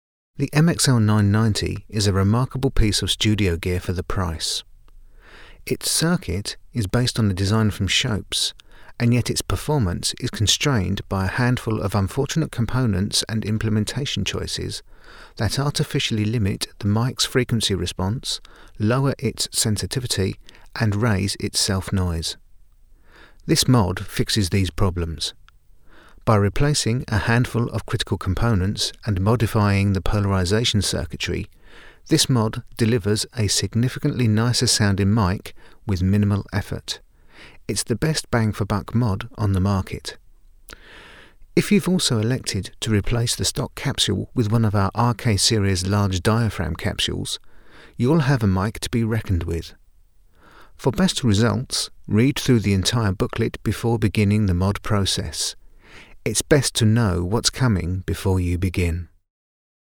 Only one diaphragm of the capsule  is connected for a cardioid response.
Even having only done the quick spoken word recording test, I am very happy with the new revoiced sound. This is not just by way of self-justification, but to my ears, it definitely sounds like a more refined and… well… more expensive microphone.
Now that the mod is complete, I have repeated the test recording with all input settings set as closely to those for the previous recording as possible.
Modded MXL990 dry
Mod990-dry.mp3